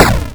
spike_trap_a.wav